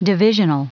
Prononciation du mot divisional en anglais (fichier audio)
Prononciation du mot : divisional